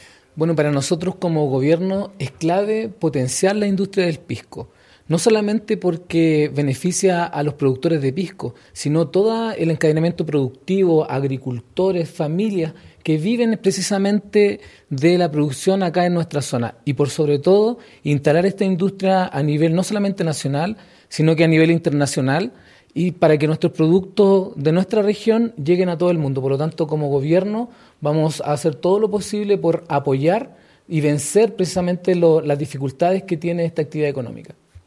AUDIO : Seremi de Gobierno, Fernando Viveros,
FERNANDO-VIVEROS-SEREMI-DE-GOBIERNO.mp3